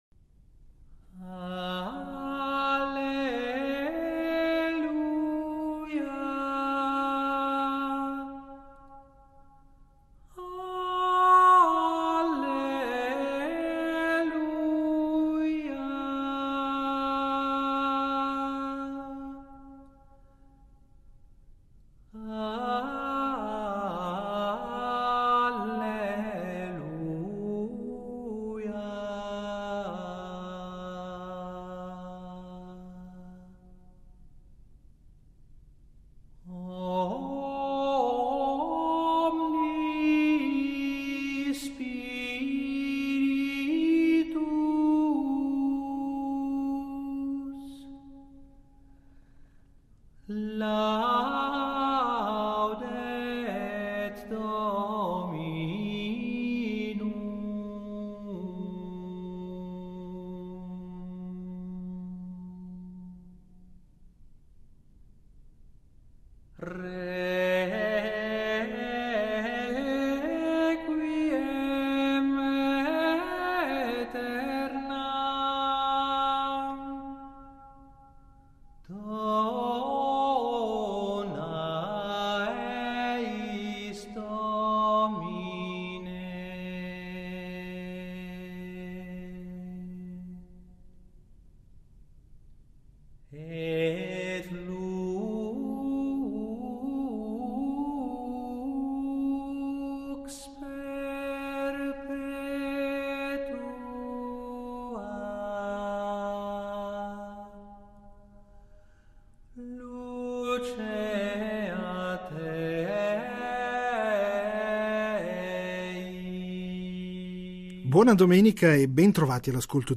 La guerra, l'escalation, il grande disordine globale visto da Pechino. Il vertice di Xi Jinping con i paesi dell'Asia centrale ad Astana. Ospite in studio